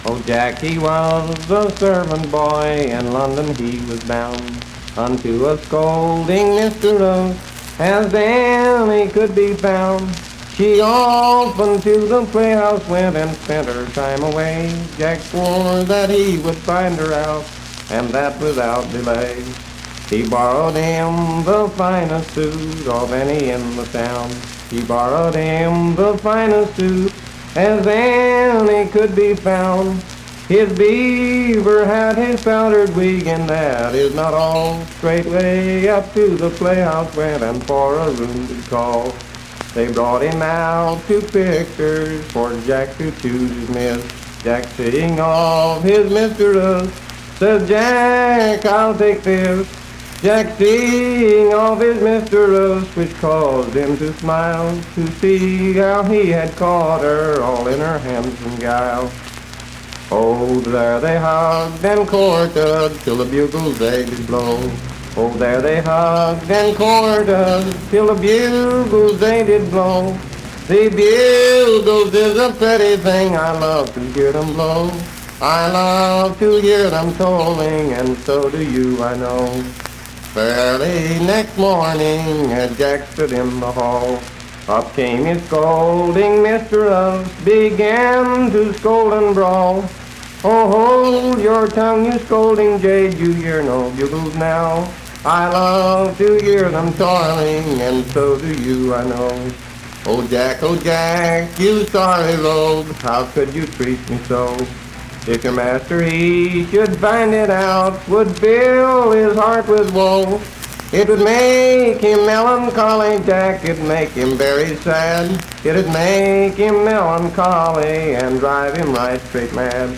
Unaccompanied vocal performance
Voice (sung)
Roane County (W. Va.), Spencer (W. Va.)